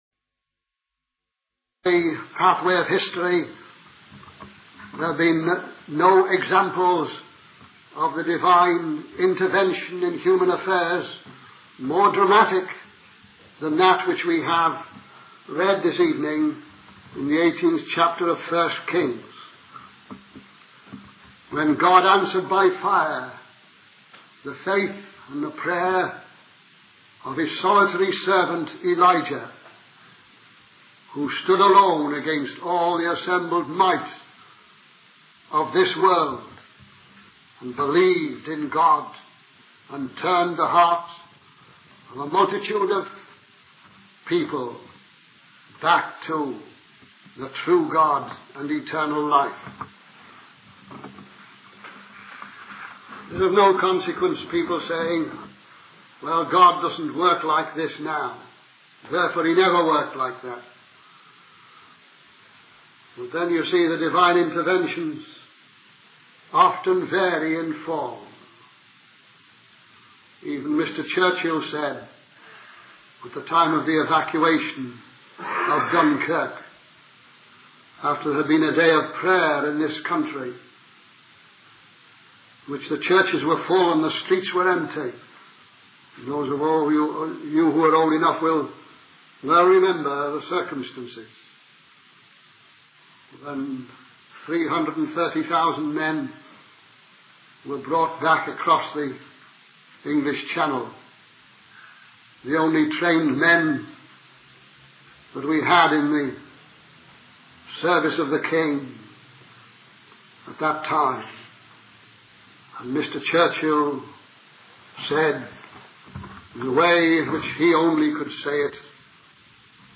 In this sermon, the speaker reflects on a significant event in history that had far-reaching consequences. He emphasizes the importance of faith and perseverance in times of obscurity and encourages listeners not to lose heart.